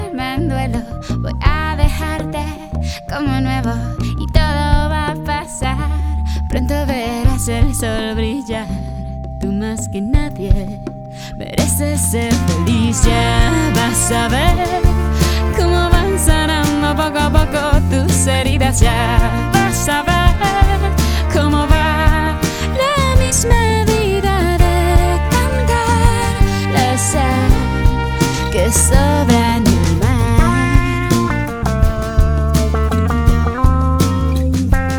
Жанр: Поп музыка / Рок / R&B / Танцевальные / Соул